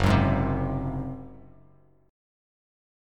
F#sus2b5 chord